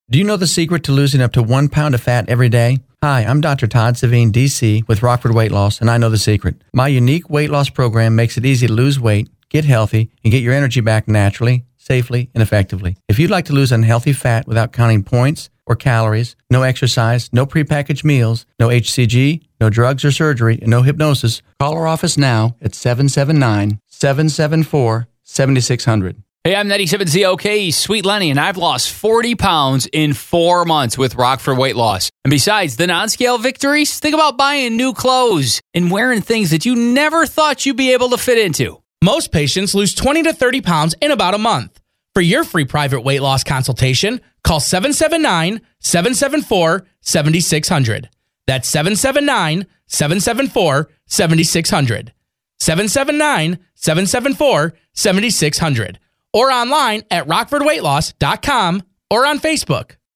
by WZOK | Radio Testimonial